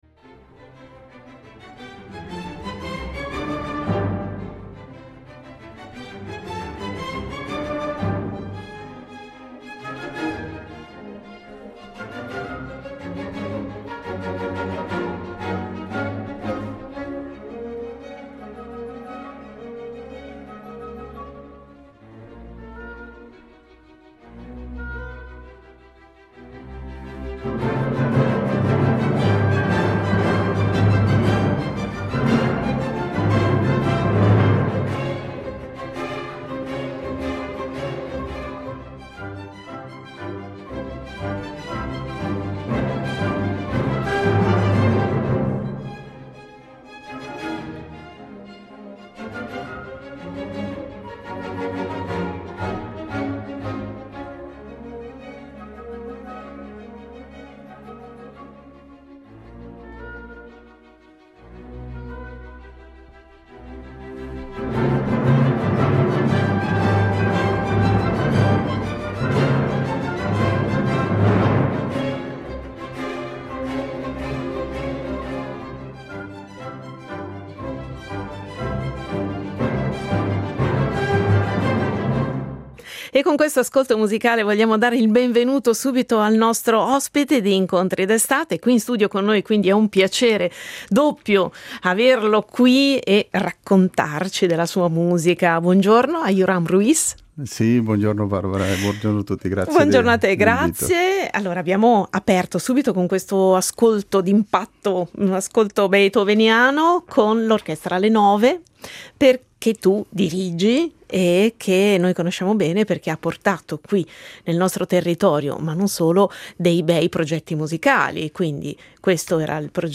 Incontro con il Direttore d’orchestra e violoncellista